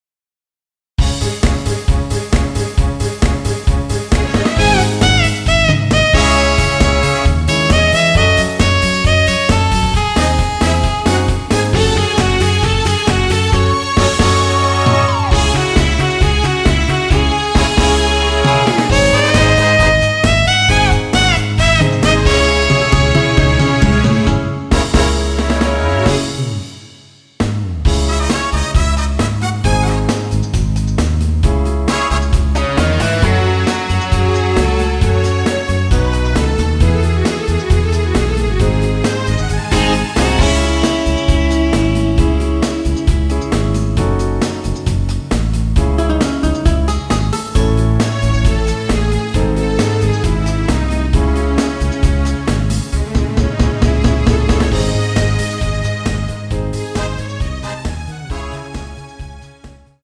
C#m
앞부분30초, 뒷부분30초씩 편집해서 올려 드리고 있습니다.
중간에 음이 끈어지고 다시 나오는 이유는